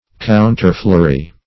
Meaning of counterfleury. counterfleury synonyms, pronunciation, spelling and more from Free Dictionary.
Search Result for " counterfleury" : The Collaborative International Dictionary of English v.0.48: Counterfleury \Coun"ter*fleu`ry\ (koun"t?r-fl?`r?), a. [F. contrefleuri.]